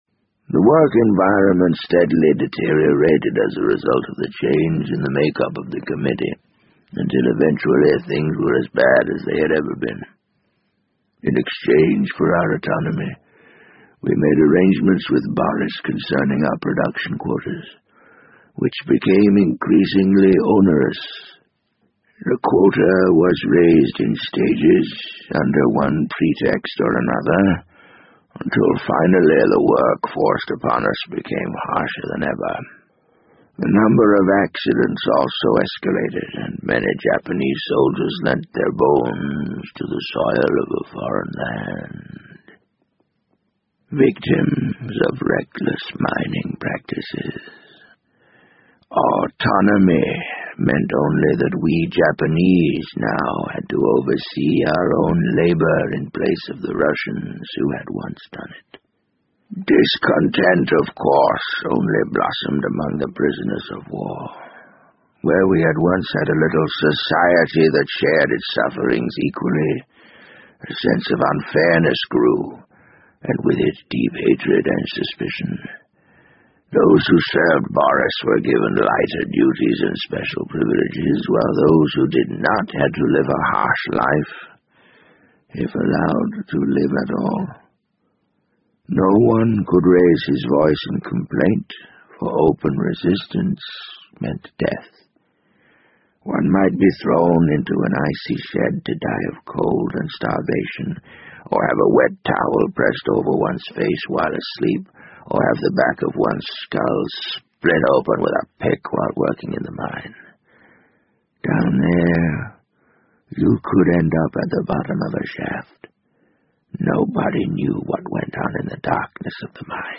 BBC英文广播剧在线听 The Wind Up Bird 014 - 14 听力文件下载—在线英语听力室